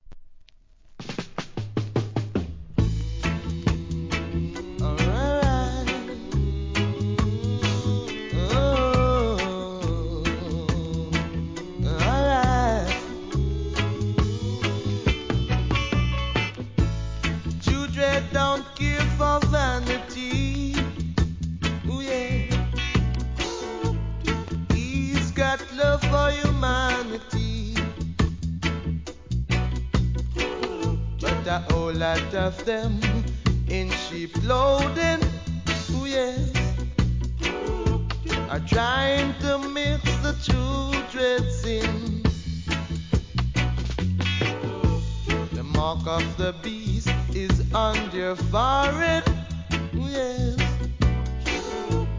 REGGAE
流石のコーラスで聴かせます!!